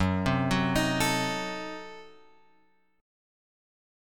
F# Diminished 7th